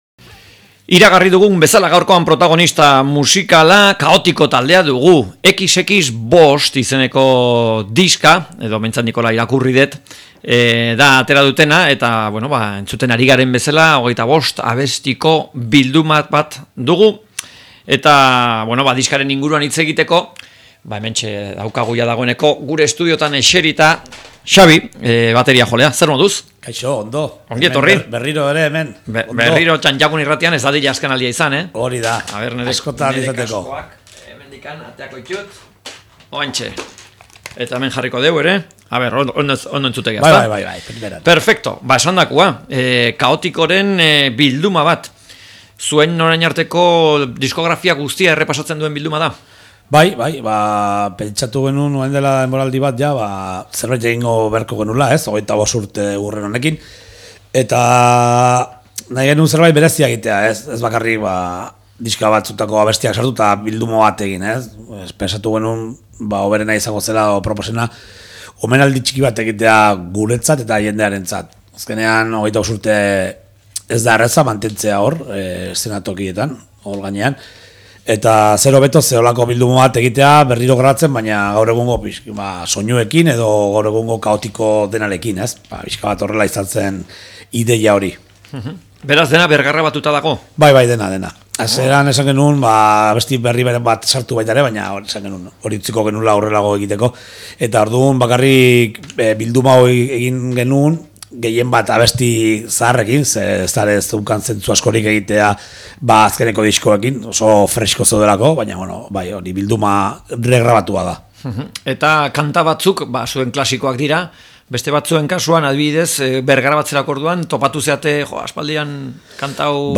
Kaotiko taldeari elkarrizketa
Kaotiko taldeak XX5 diskoa atera du eta Eguerdiko Ahotsa magazinean elkarrizketatu ditugu.